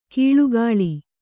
♪ kīḷugāḷi